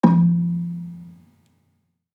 Gambang-F2-f.wav